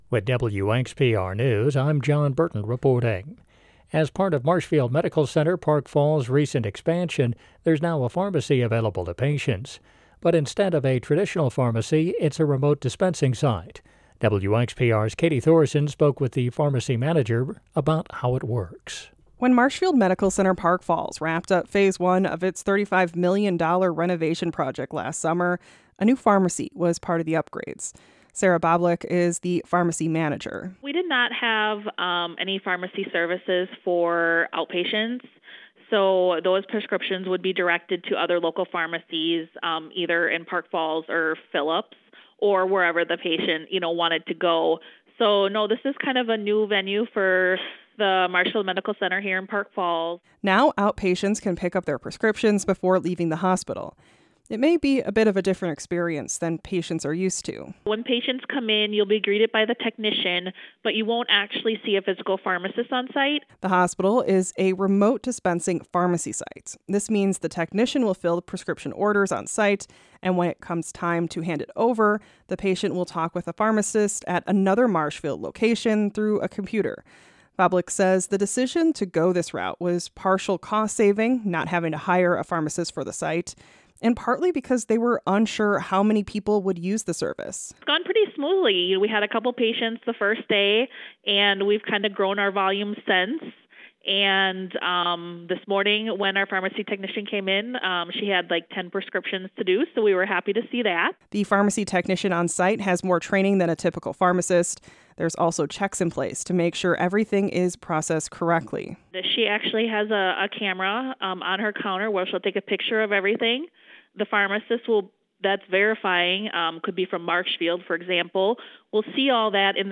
The Extra is WXPR’s daily news podcast. Get the news you need to stay informed about your local community – all in six minutes or less.